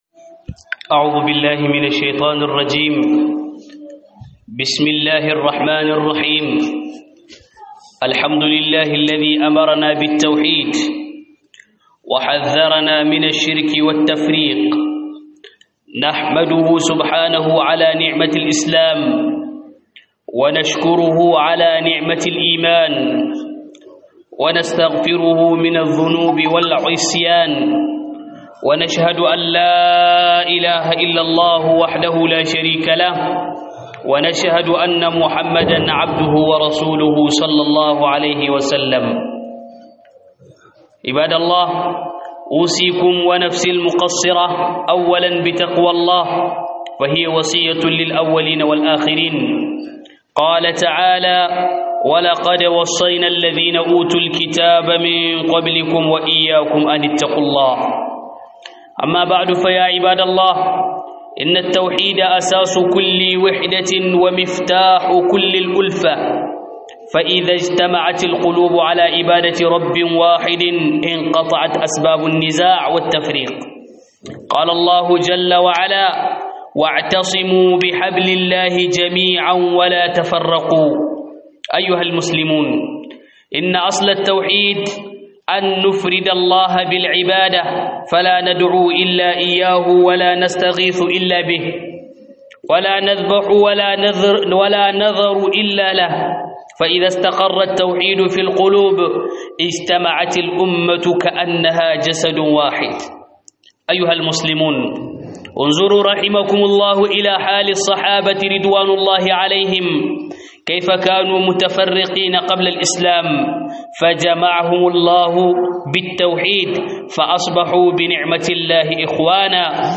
Huɗubar juma'a Tauhidi tushen haɗin kai - HUDUBA